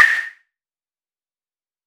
Percs
REDD PERC (28).wav